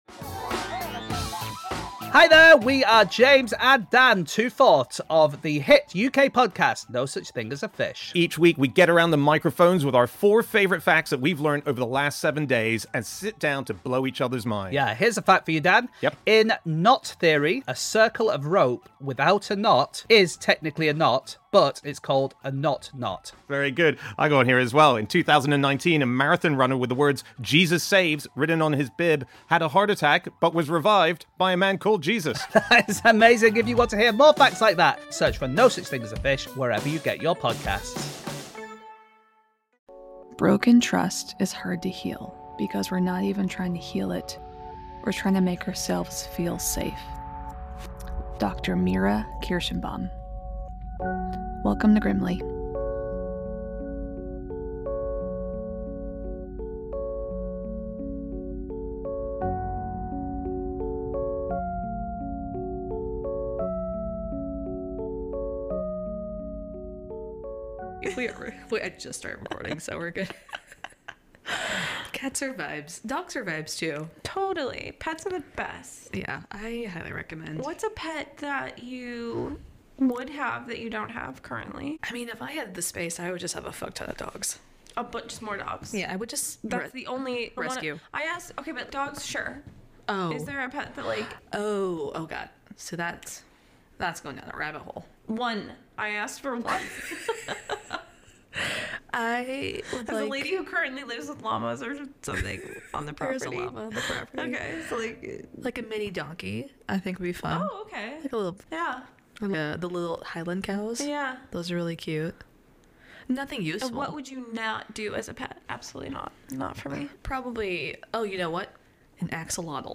This week the girls are talking about the worst/best pets, the 4B movement and like always, geography for some reason.